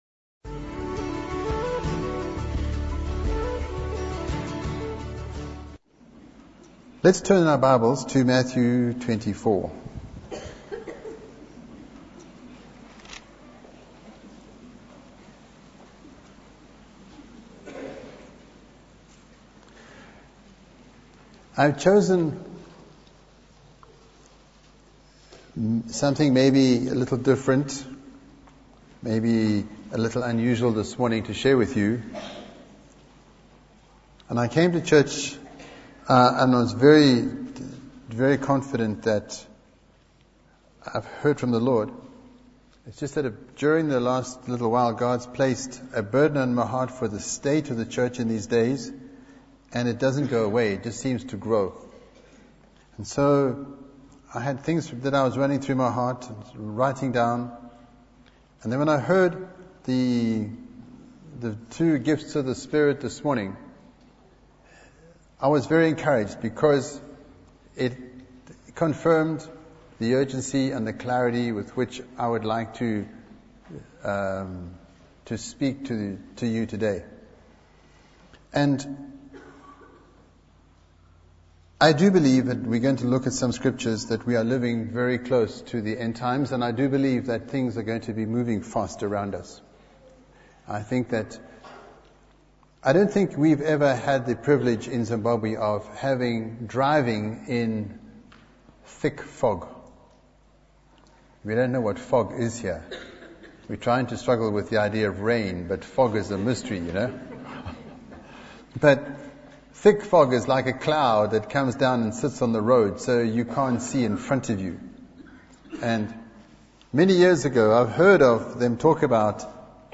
In this sermon, the speaker expresses a burden on his heart for the state of the church in these days. He emphasizes the urgency and clarity with which he wants to address the congregation. The speaker references Matthew 24, specifically the narrow gate that leads to life and the need to be ready for the unexpected return of the Son of Man.
The sermon includes an illustration involving a cross to further emphasize the message.